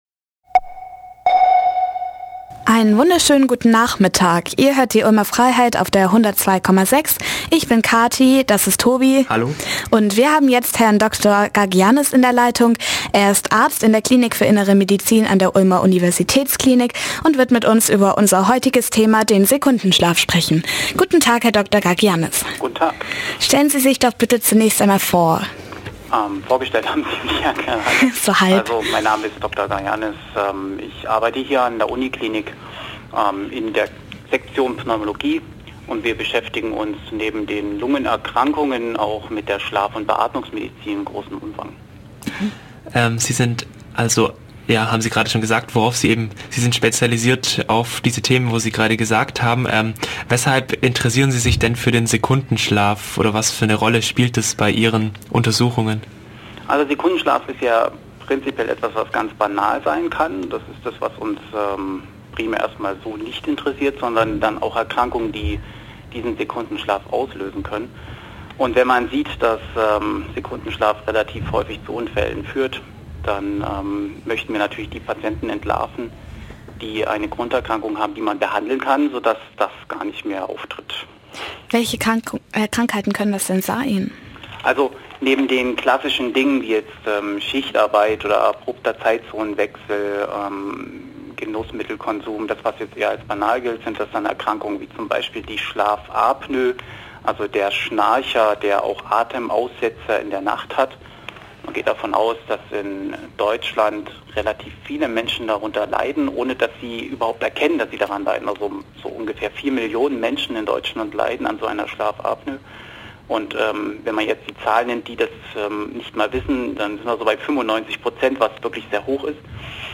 interview_sekundenschlaf.mp3